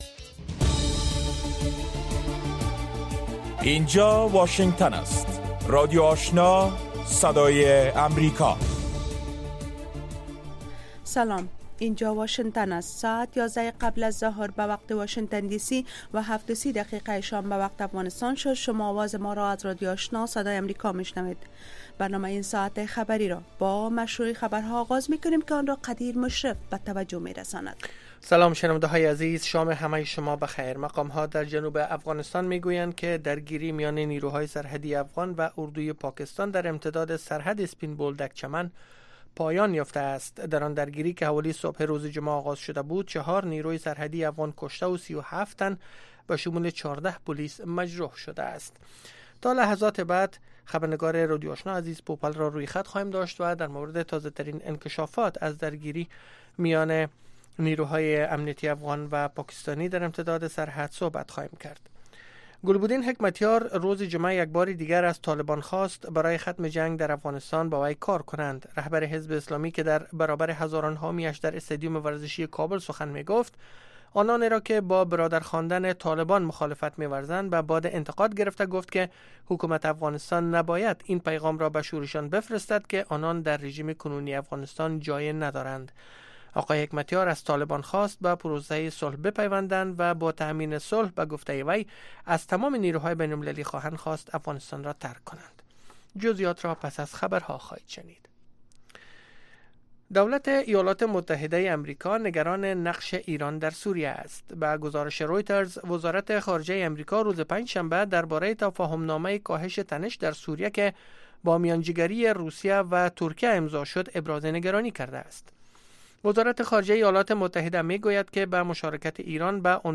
نخستین برنامه خبری شب